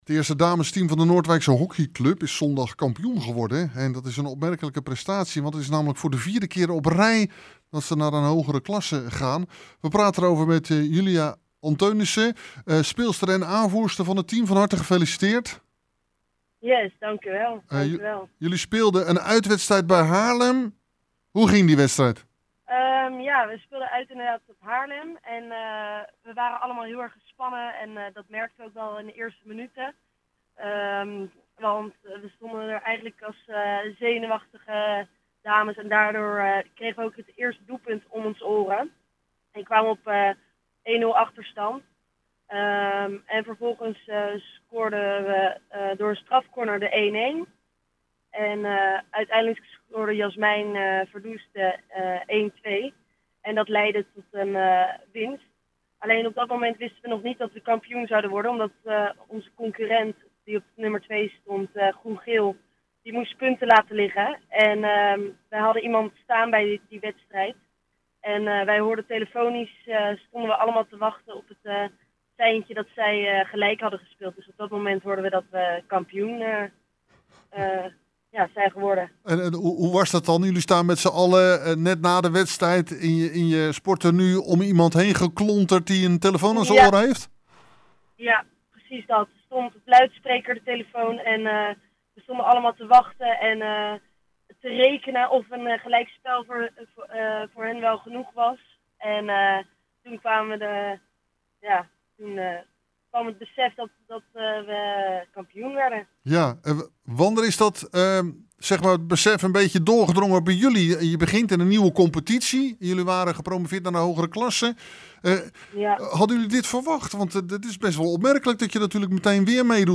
Hieronder het radio-interview: